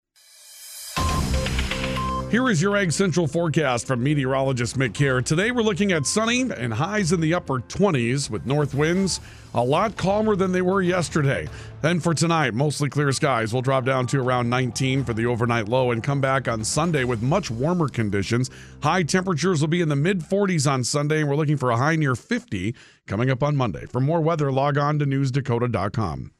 Weather Forecast